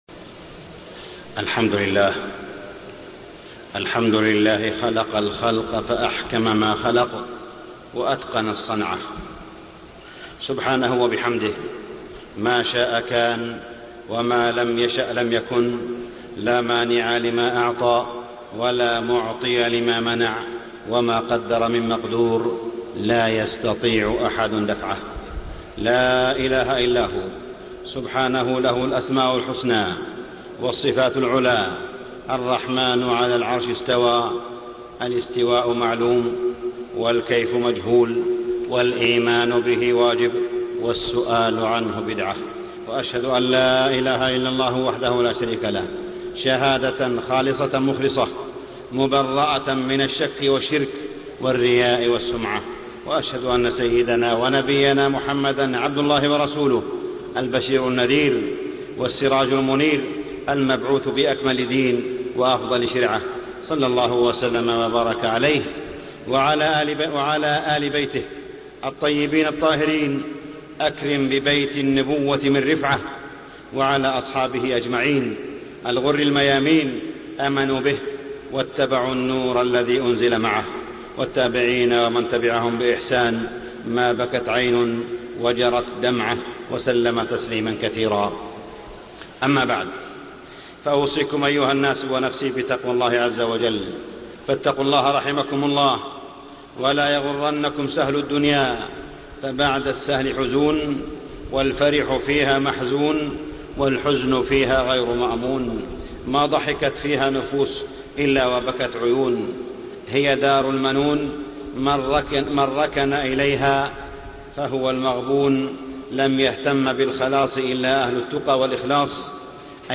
تاريخ النشر ١٣ ربيع الأول ١٤٢٦ هـ المكان: المسجد الحرام الشيخ: معالي الشيخ أ.د. صالح بن عبدالله بن حميد معالي الشيخ أ.د. صالح بن عبدالله بن حميد أين البركة أين الطمأنينة